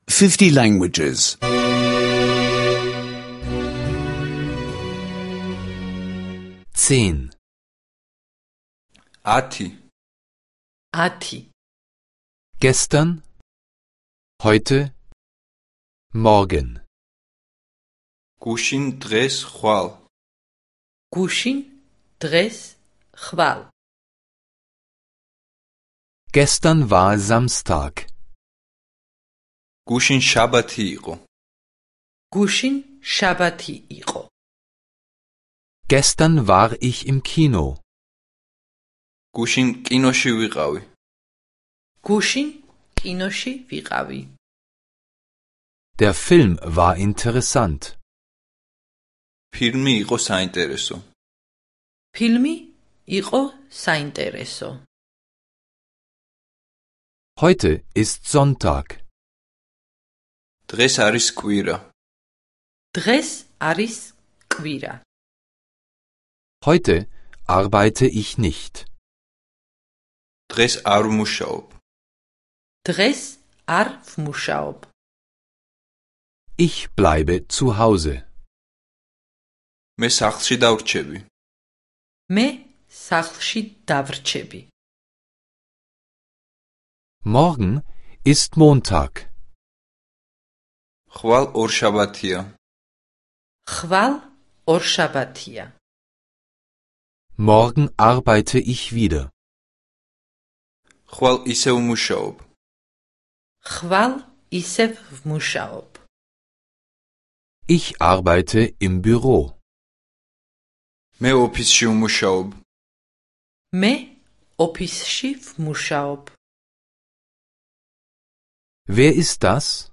Georgische Audio-Lektionen, die Sie kostenlos online anhören können.